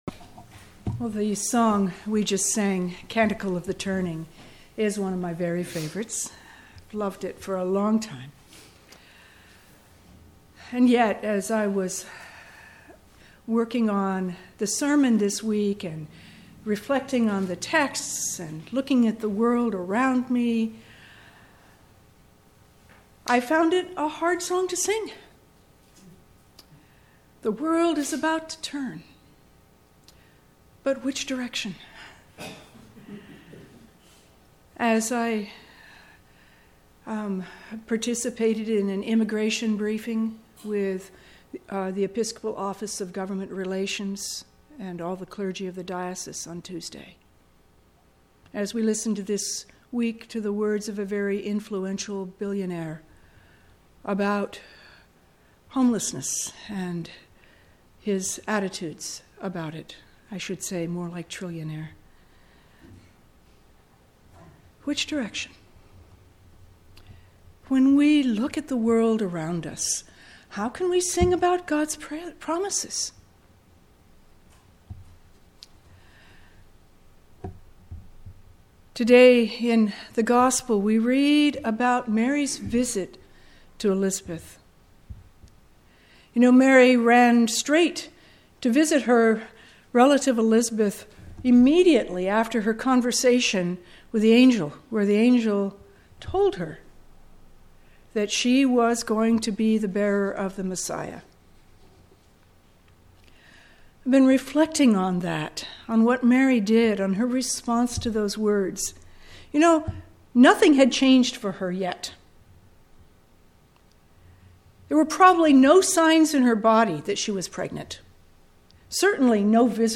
Sermons, St. Stephen Episcopal Church